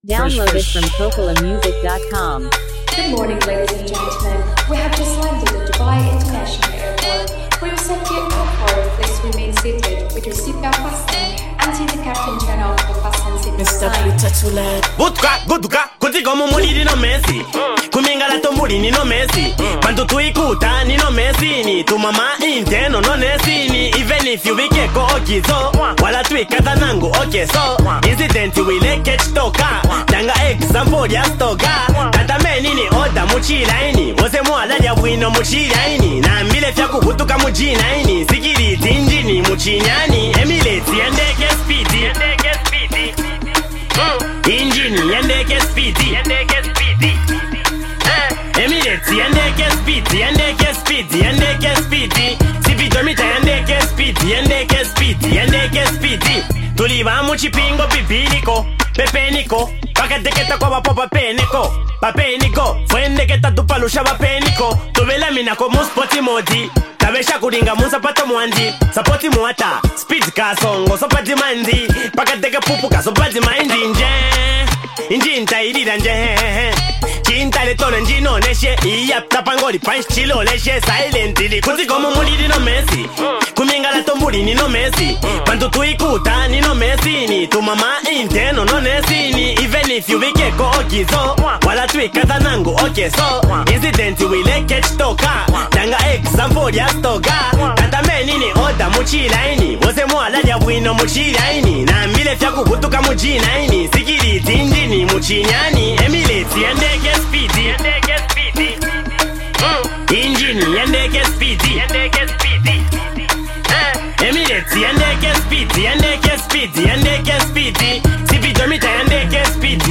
high-energy track